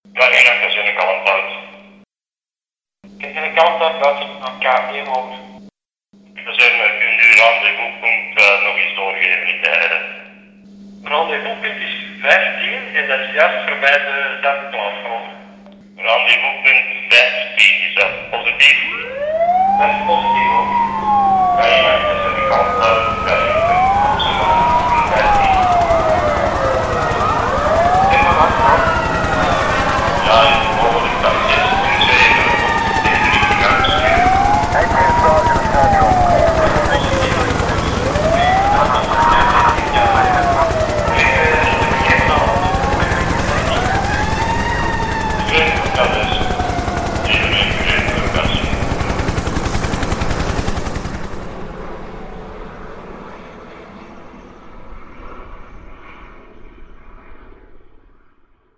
Sous-catégorie Musique de concert
Instrumentation Ha (orchestre d'harmonie)